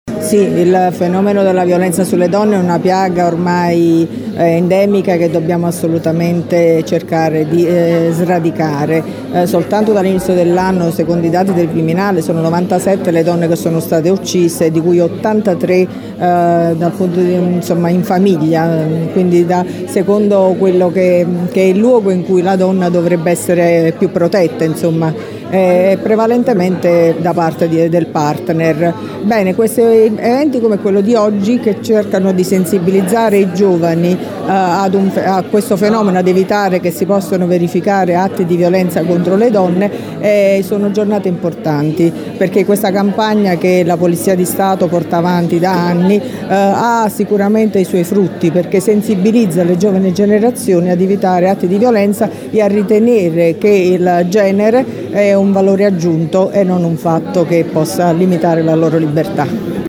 L’evento, che precede la Giornata Internazionale per l’eliminazione della violenza contro le donne, si è tenuto nella sala conferenze del Polo Pontino della Sapienza con la partecipazione di molti attori istituzionali.
Le interviste contenute in questo articolo
PREFETTA DI LATINA VITTORIA CIARAMELLA